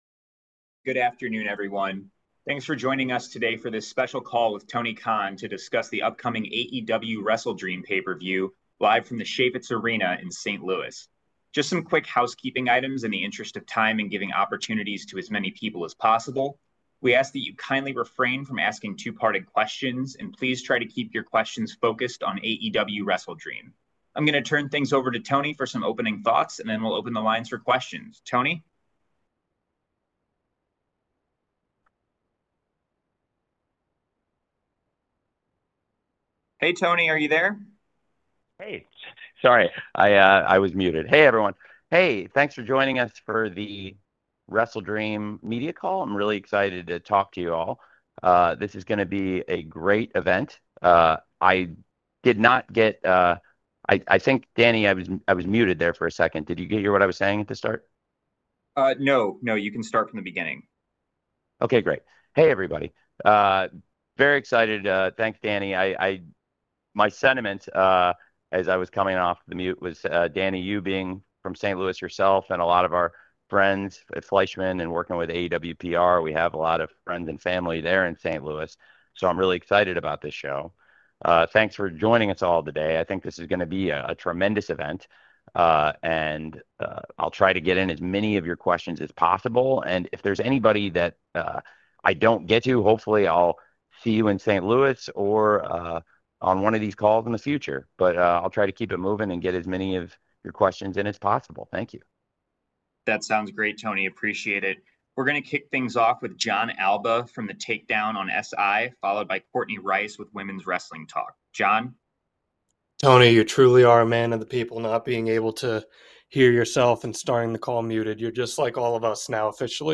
During a Thursday media call, AEW head Tony Khan talked about the contract situation with Andrade El Idolo (somewhat) in addition to the new ratings system, injury updates to two stars, potentially two Blood & Guts matches, and more.
The full audio from the call can be found below.